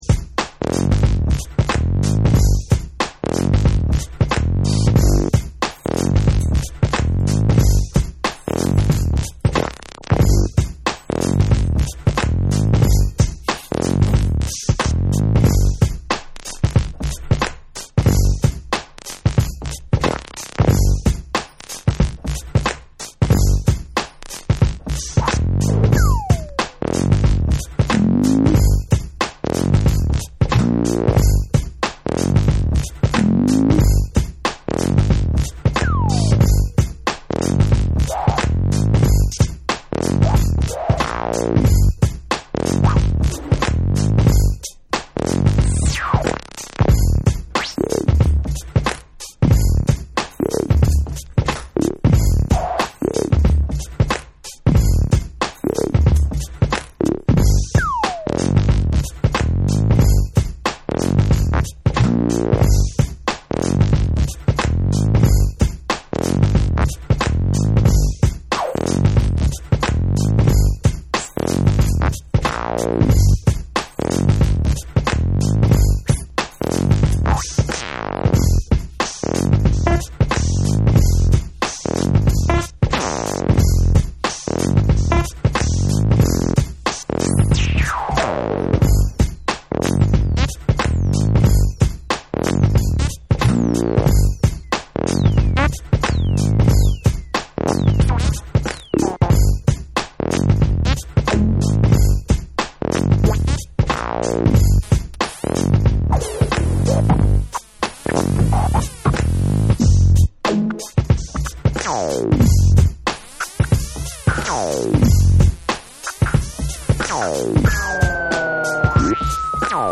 こちらも印象の強いベース・ラインが特徴的なユーモア溢れるブレイクビーツの2。
BREAKBEATS